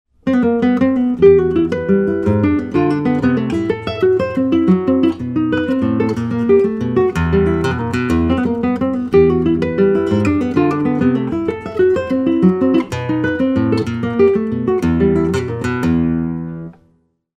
Torres FE - 17 style guitar
European Spruce soundboard, European Maple back & sides-